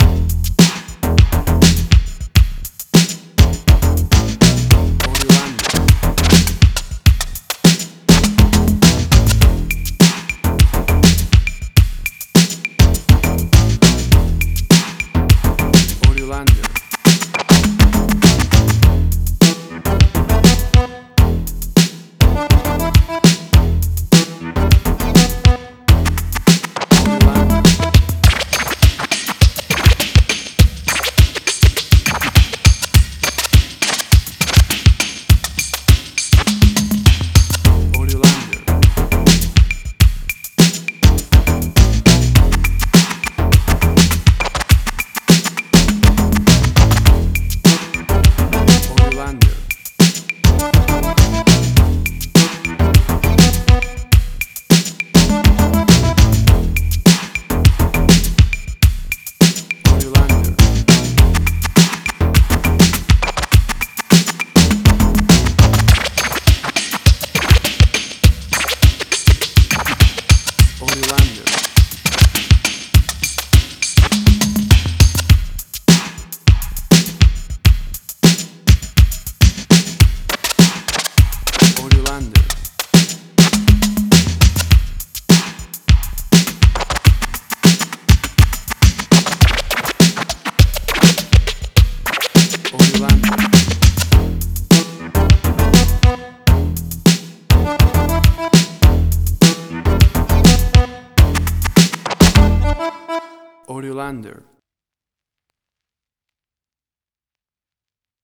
emotional music
Tempo (BPM): 103